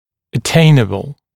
[ə’teɪnəbl][э’тэйнэбл]достижимый